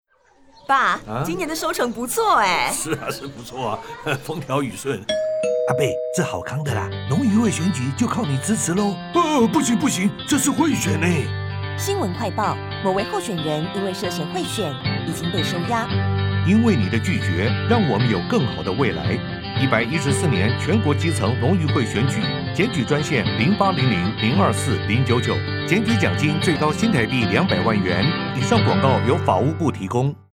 114年度全國基層農漁會選舉法務部反賄選宣導30秒廣播帶_國語.mp3